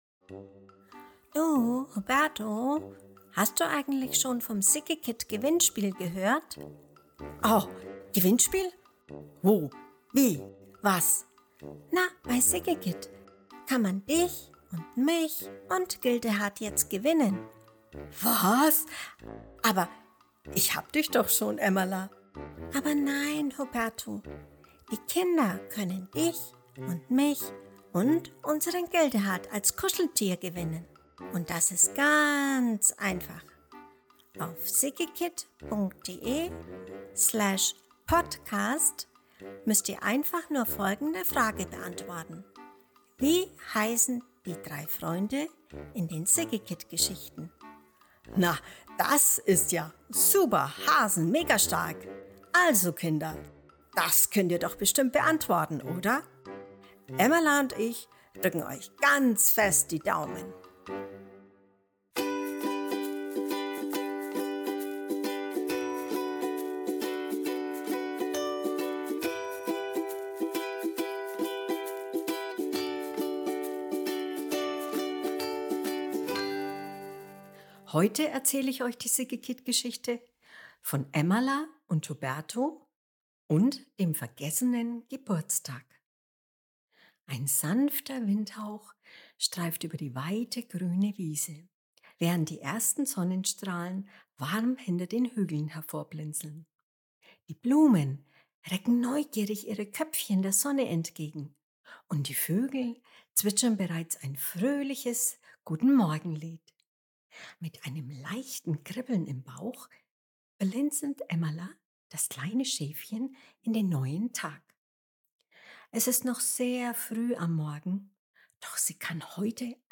März 2025 Kinderblog Vorlesegeschichten, Emmala & Huberto Es ist noch sehr früh am Morgen, doch Emmala, das kleine Schäfchen kann heute einfach nicht mehr schlafen.